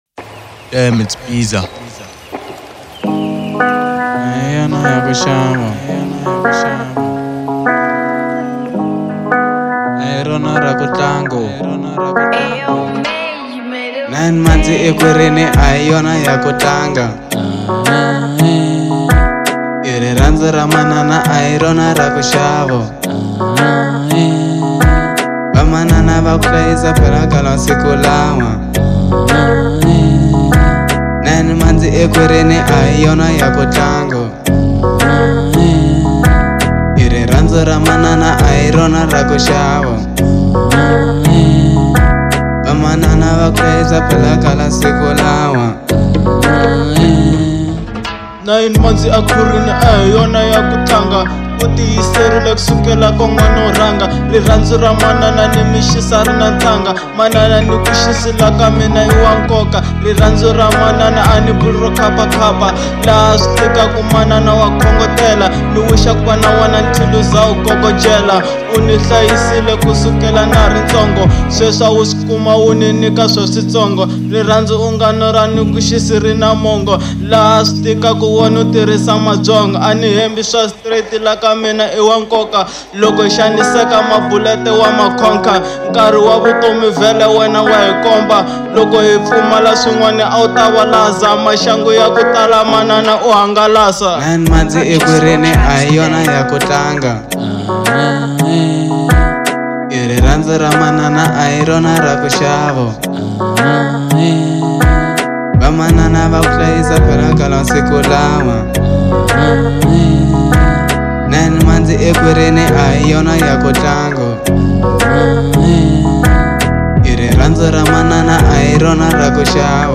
03:20 Genre : Hip Hop Size